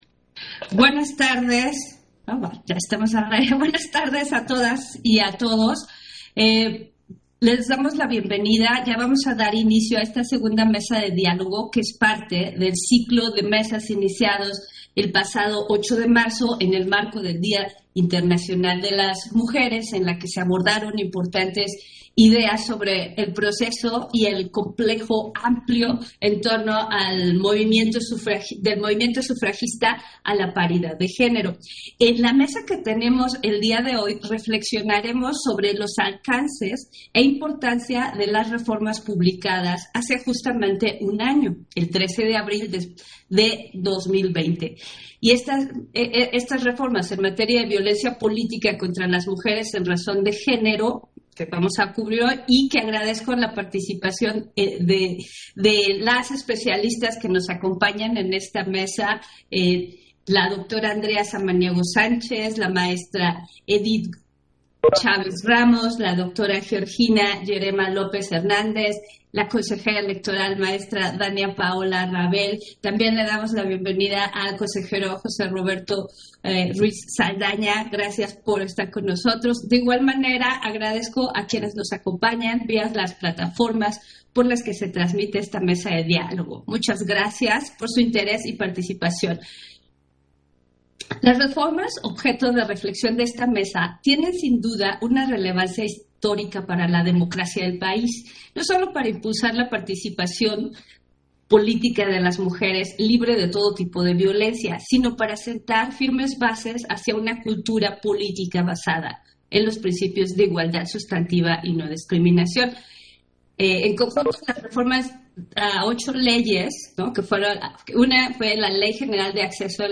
Intervención de Norma de la Cruz, durante la Mesa de Diálogo: A un año de las reformas sobre Violencia Política Contra las Mujeres